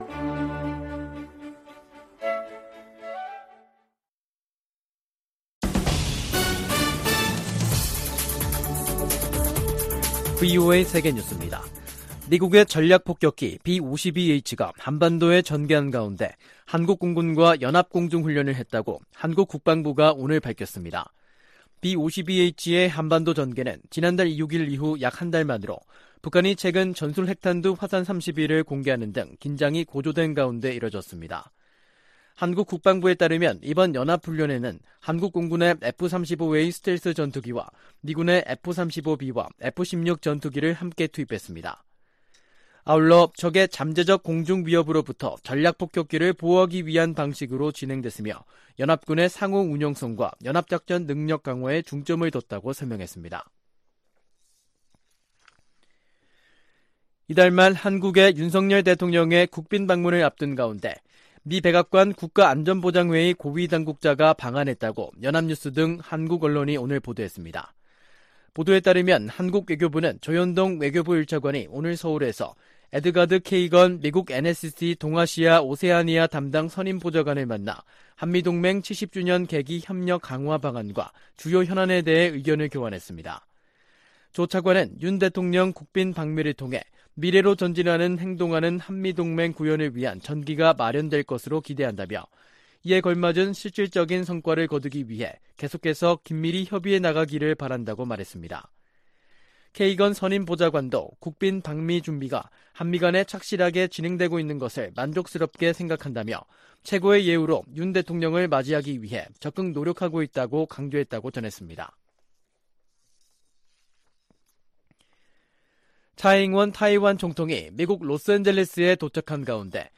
VOA 한국어 간판 뉴스 프로그램 '뉴스 투데이', 2023년 4월 5일 2부 방송입니다. 유엔 인권이사회가 북한의 조직적인 인권 침해를 규탄하고 개선을 촉구하는 내용의 북한인권결의안을 채택했습니다. 미국 국무장관이 현재 당면한 도전은 세계적인 것이라며 나토와 아시아 국가들의 협력 확대 중요성을 강조했습니다. 미 공군이 시험 발사할 대륙간탄도미사일(ICBM) 미니트맨 3는 북한 정권을 끝낼 수 있는 위력을 가진 무기라고 미 군사 전문가들이 평가했습니다.